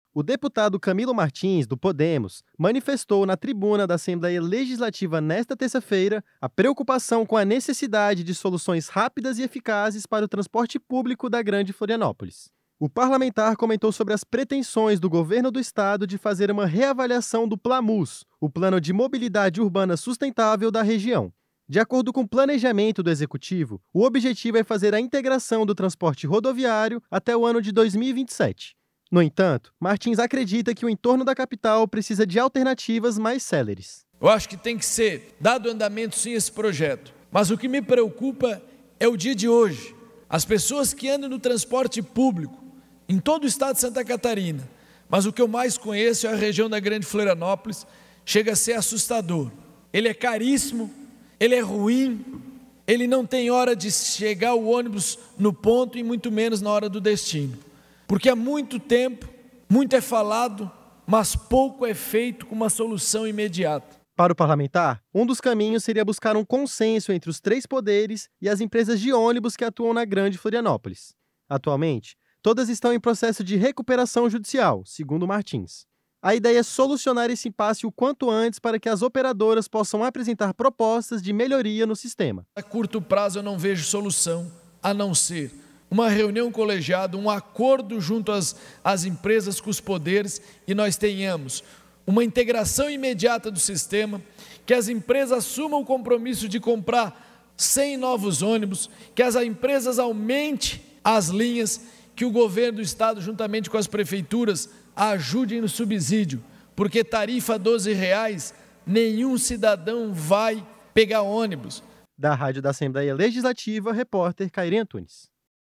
O deputado Camilo Martins (Podemos) manifestou na tribuna da Assembleia Legislativa nesta terça-feira (11) a preocupação com a necessidade de soluções rápidas e eficazes para o transporte público da Grande Florianópolis.
Entrevista com:
- deputado Camilo Martins (Podemos).